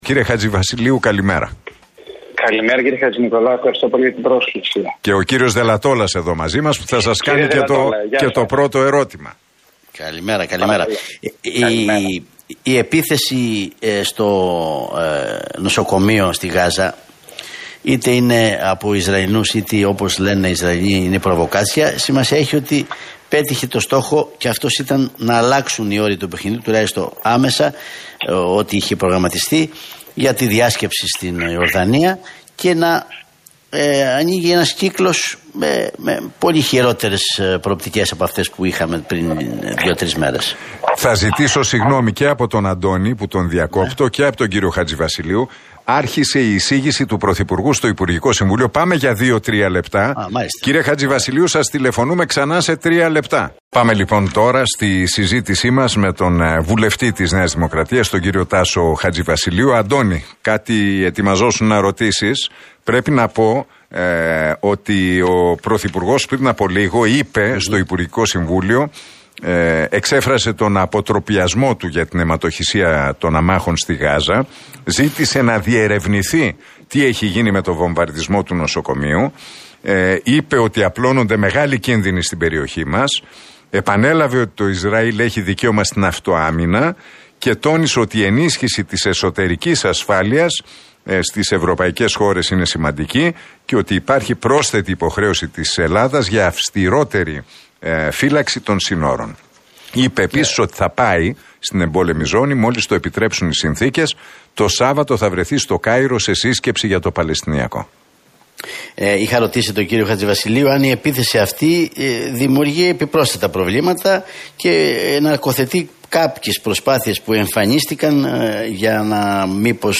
Χατζηβασιλείου στον Realfm 97,8: Η σφαγή στο νοσοκομείο στην Γάζα ακυρώνει κάθε ειρηνευτική πρωτοβουλία